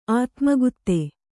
♪ ātmagutte